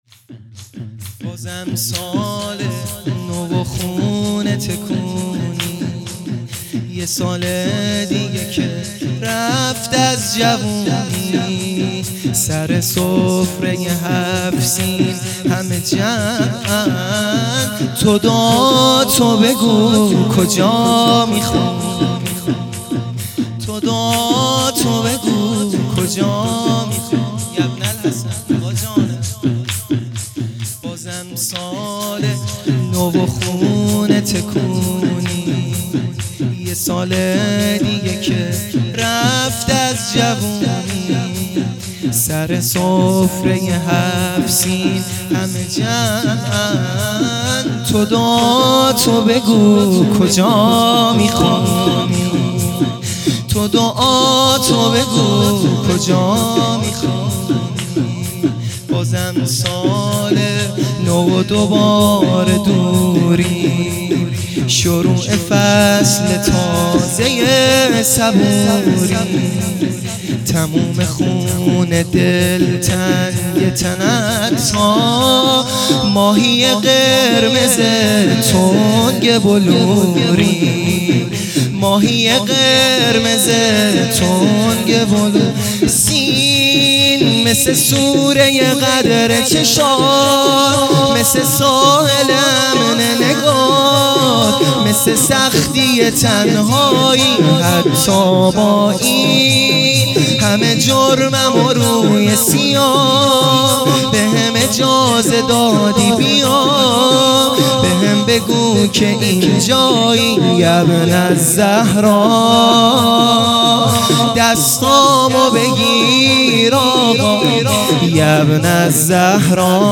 شور احساسی ا بازم سال نو و خونه تکونی